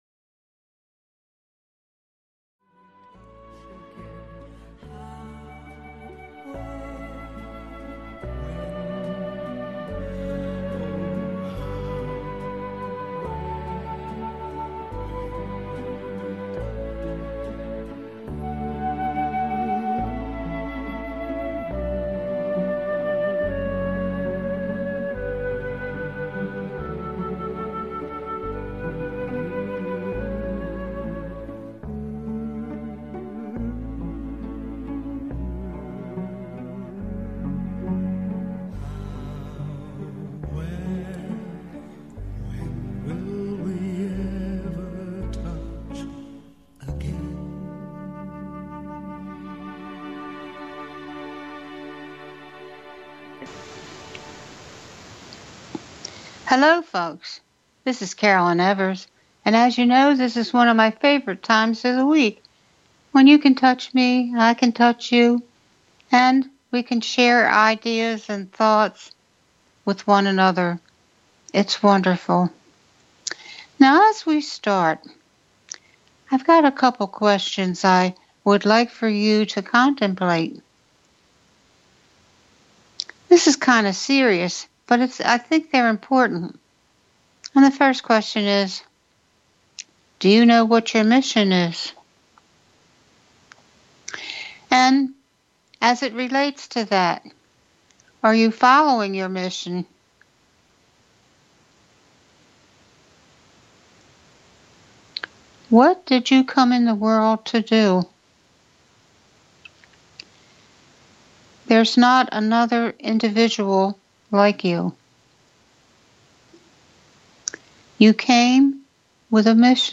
Live Psychic Readings
Talk Show